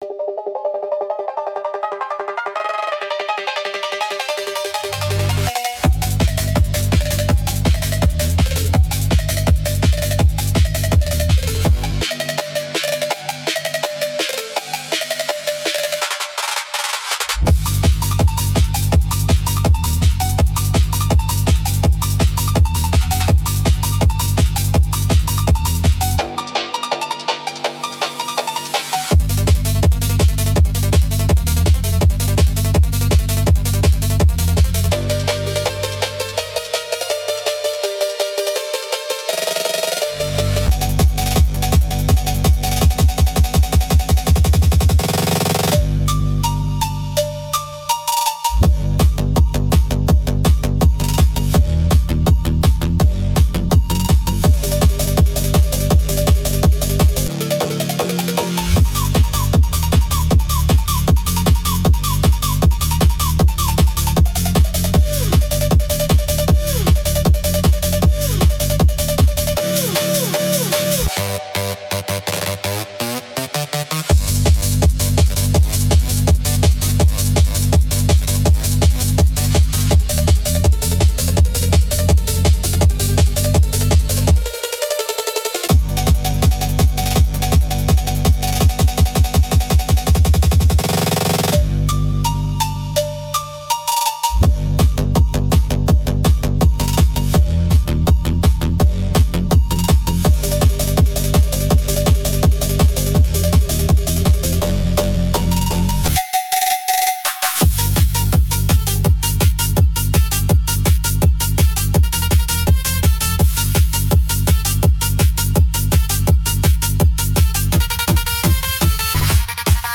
There are no lyrics because it is an instrumental.